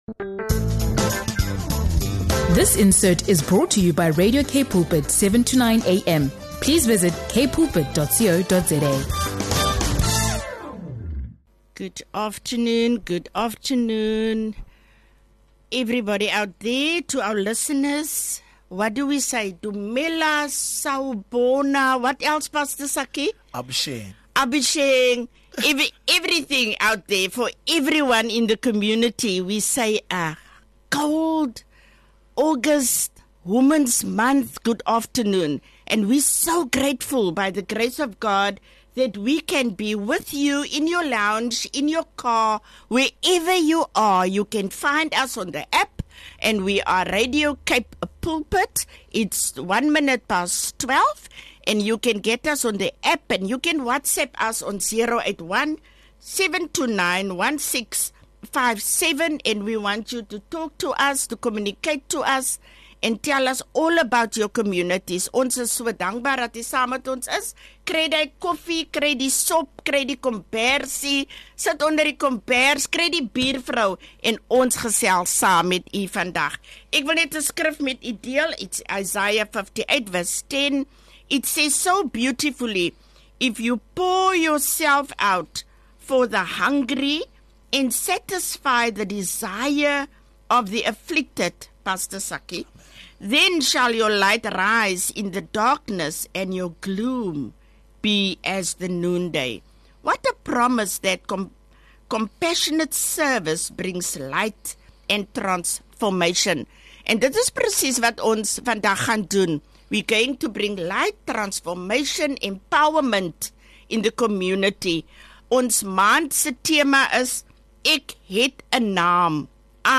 Tune in for an enlightening and inspiring conversation.